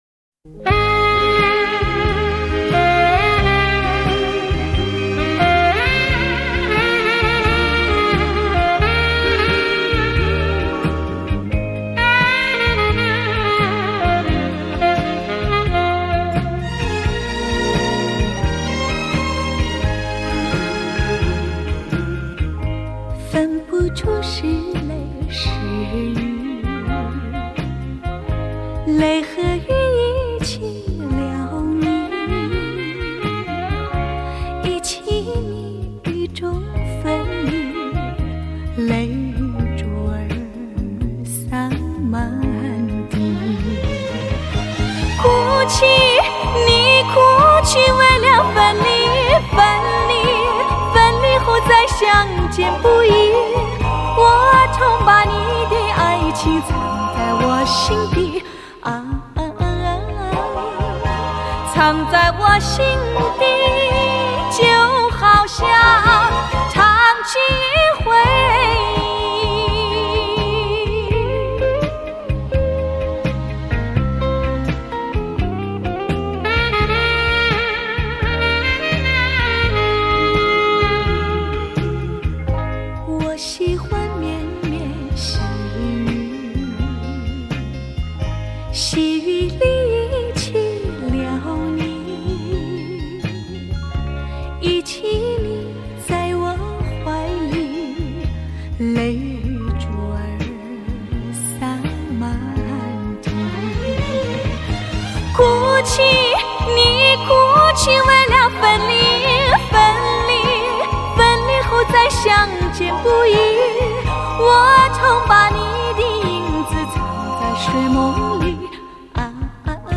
以当今至高规格音效处理
DXD重新编制